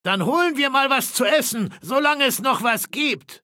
Fallout 3: Audiodialoge
Beschreibung Charakter: Bill Seward Lizenz Diese Datei wurde in dem Video-Spiel Fallout 3 aufgenommen oder stammt von Webseiten, die erstellt und im Besitz von Bethesda Softworks oder Obsidian Entertainment sind, deren Urheberrecht von Bethesda Softworks oder Obsidian Entertainment beansprucht wird.